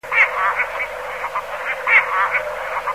głosy innych kormoranów
Kormoran Mały - Phalacrocorax Pygmeus (do Polski zalatuje sporadycznie)mp323 kb